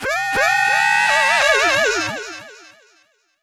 VOICEFX242_TEKNO_140_X_SC2(L).wav